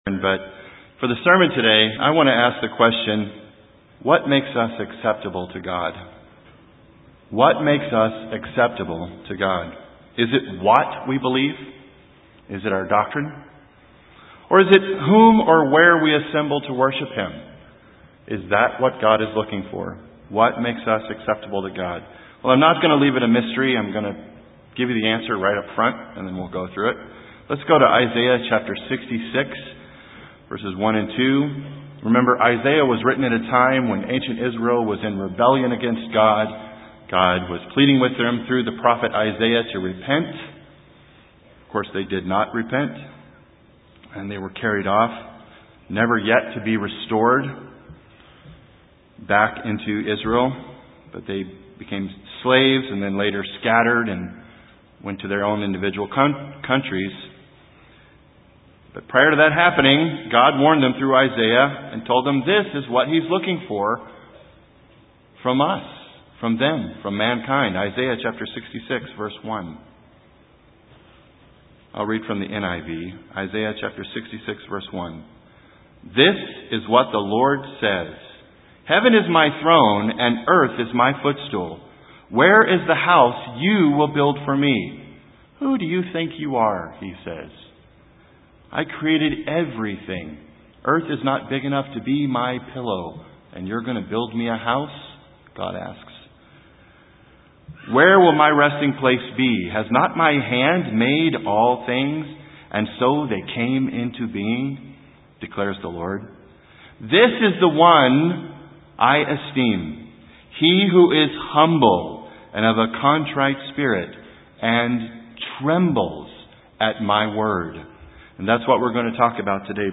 This sermon helps us understand the answer to the question of what makes us acceptable to God.